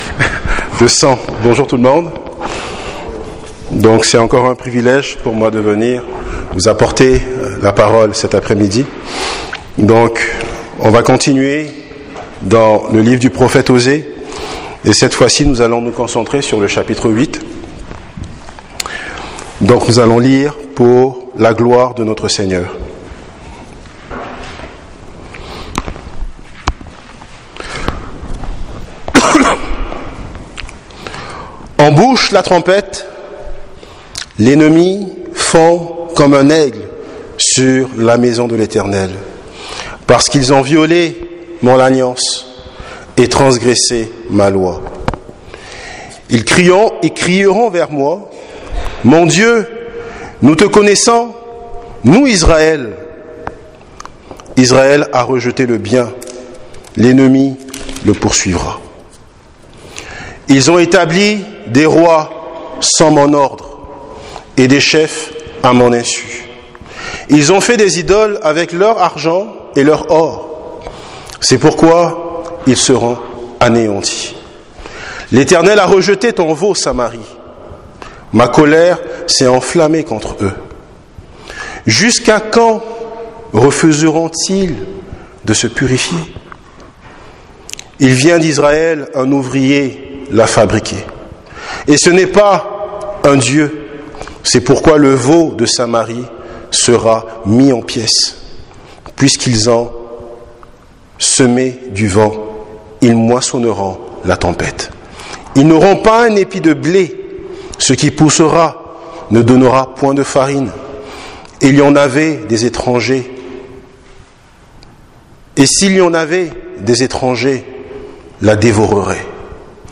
Serie de sermons